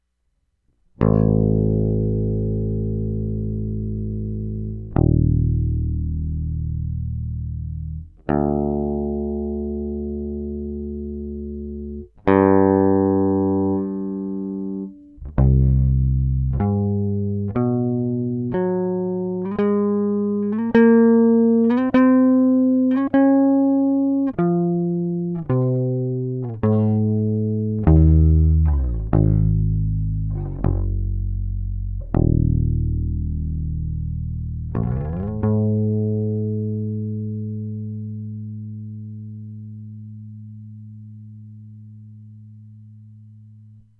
比較的ノイズも少ないように思いますがノイズの面やレイテンシーの問題からASIO4ALLを利用するのがよいと思います。
需要あるのかわかりませんがiO2とUR28Mとで適当に弾いたプレベの素の録音サンプルおいときますね。
bass_io2.mp3